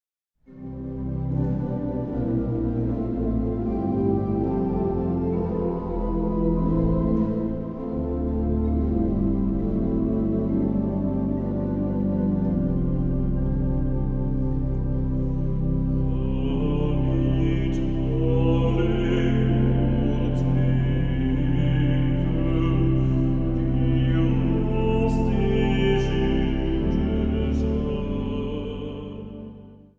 Zang | Solozang